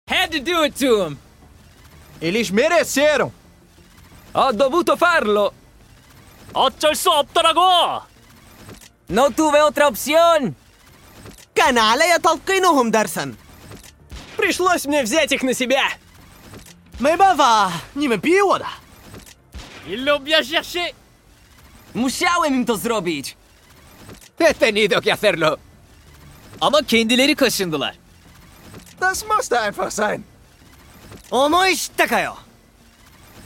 Gekko Ace Voice Lines in sound effects free download
Gekko Ace Voice Lines in Every Language (Part 3) | Valorant